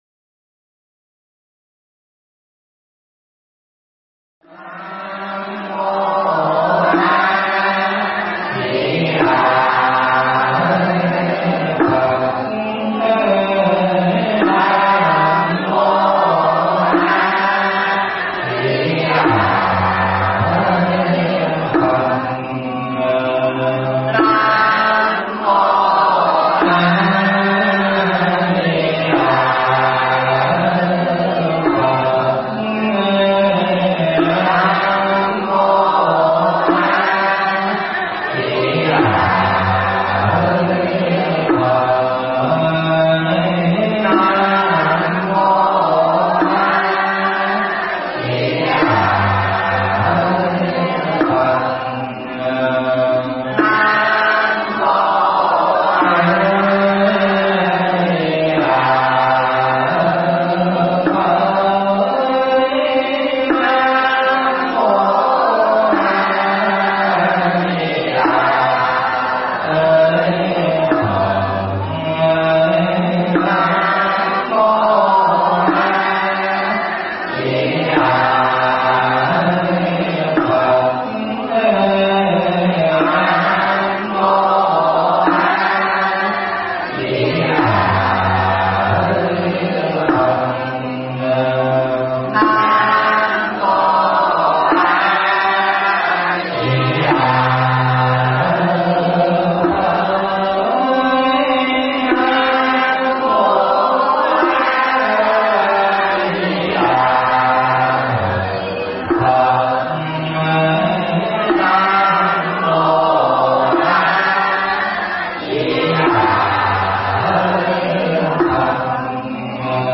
Mp3 Pháp Thoại Biết Và Sống Bằng Trí Tuệ Phần 1
giảng tại Chùa Phước Linh